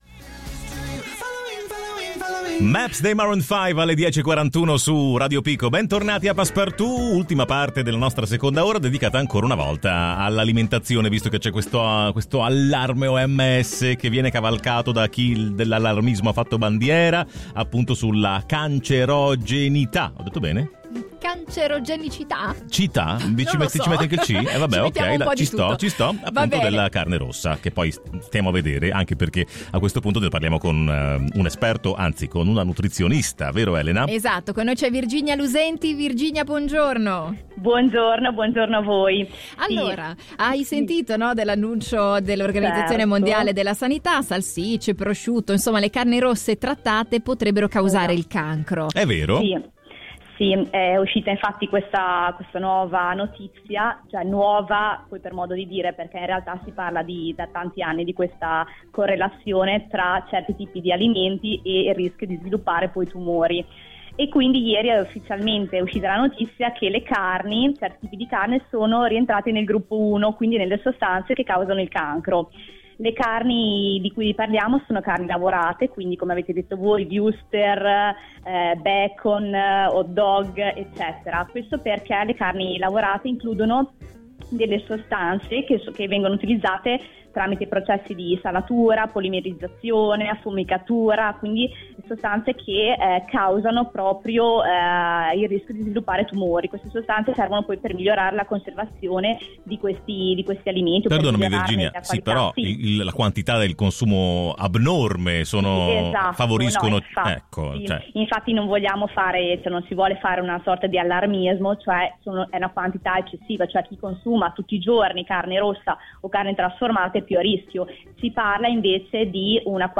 Abbiamo intervistato